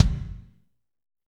Index of /90_sSampleCDs/Northstar - Drumscapes Roland/DRM_Fast Rock/KIK_F_R Kicks x